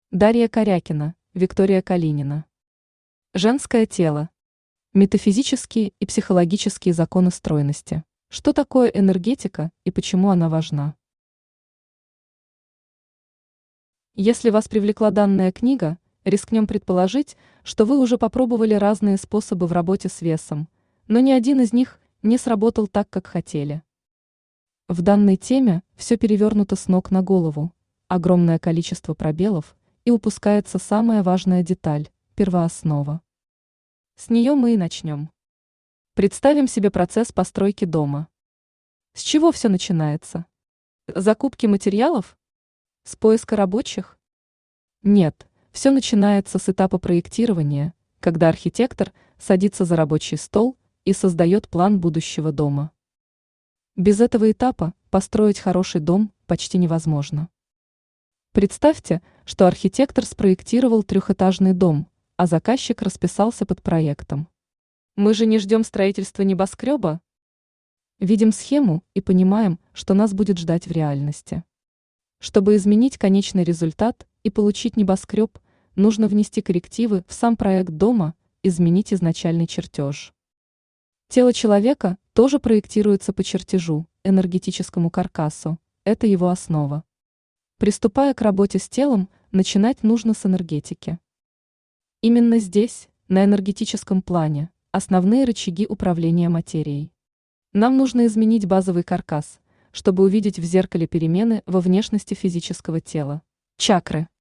Аудиокнига Женское тело. Метафизические и психологические законы стройности | Библиотека аудиокниг
Метафизические и психологические законы стройности Автор Дарья Корякина Читает аудиокнигу Авточтец ЛитРес.